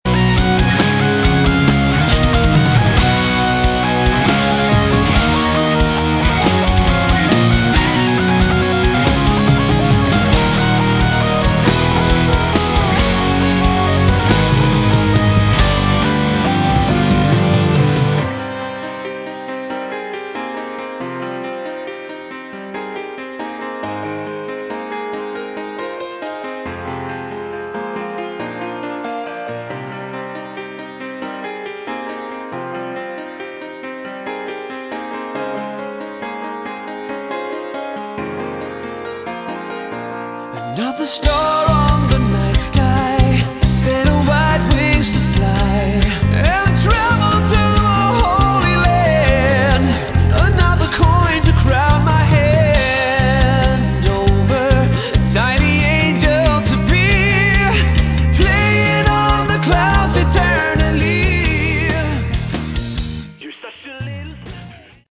Category: Prog Rock
bass
guitar
vocals
keyboard
drums